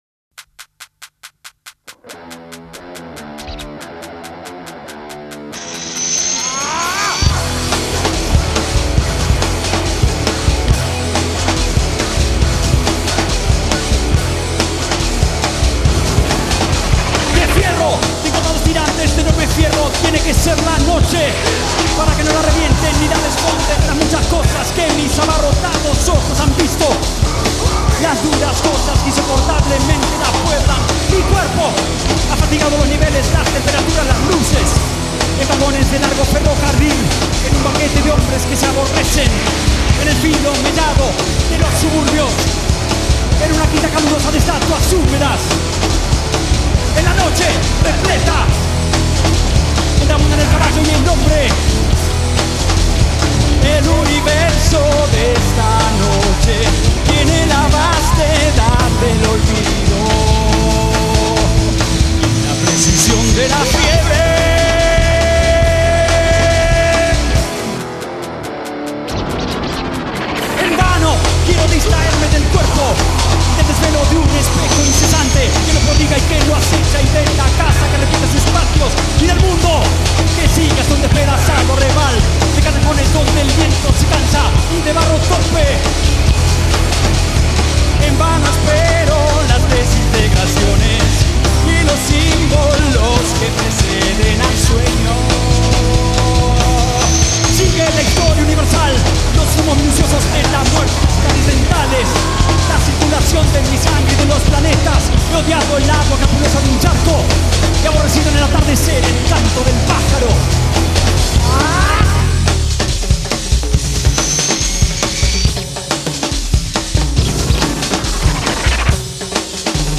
voz
guitarra
teclados
percusión